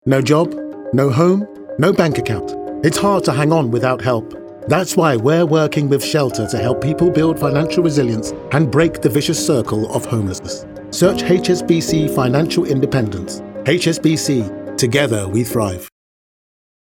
HSBC Commercial. Calm, Genuine, Clear https